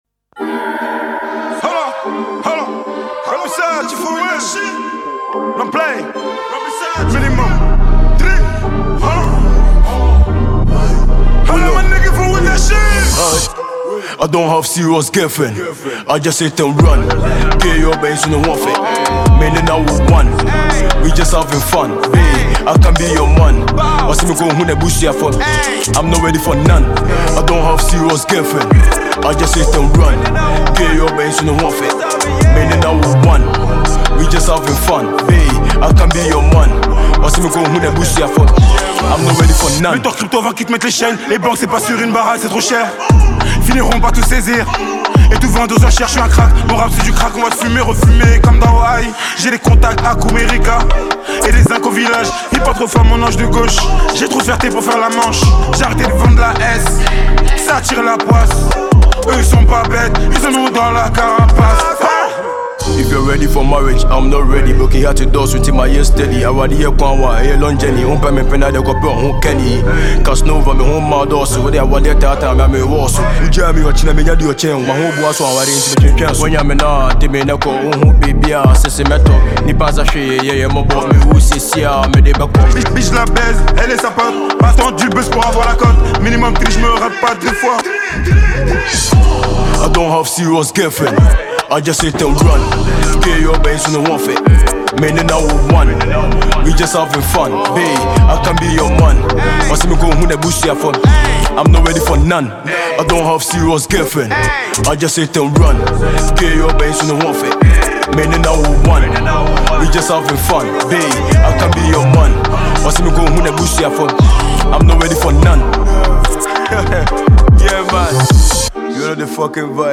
a talented Ghanaian asakaa rapper